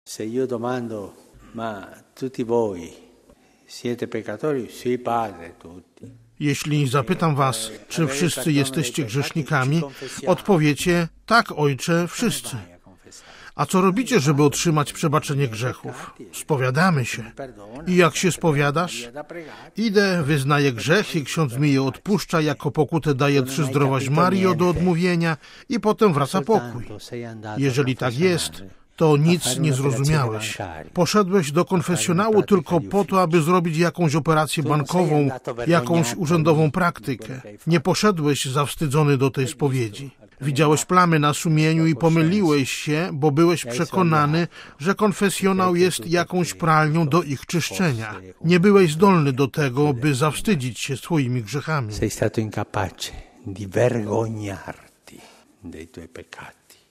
Jeśli nie będziemy mieć świadomości, że Bóg przebaczył nam grzechy, sami nigdy nie będziemy umieli wybaczyć – mówił Papież na Mszy w Domu św. Marty. W kazaniu odniósł się do czytanej dziś Ewangelii o niemiłosiernym słudze.